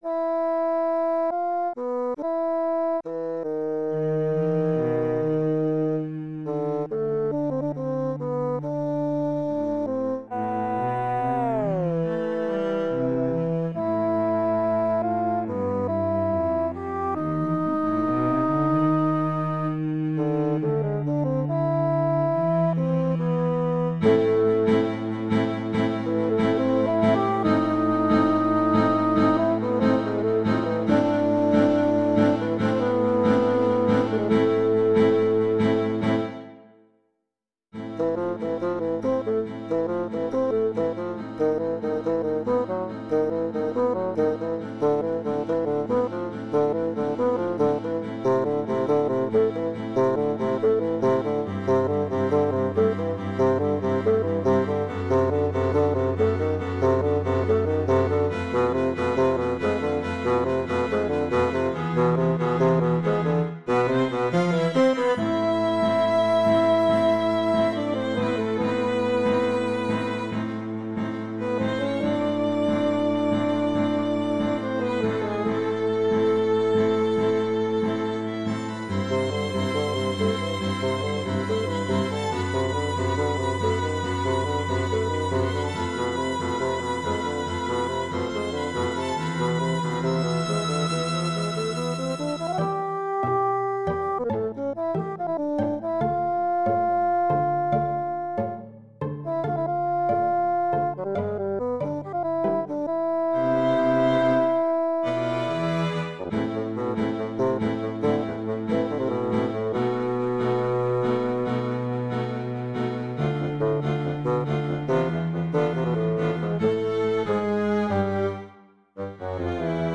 adaptação para fagote e quarteto de cordas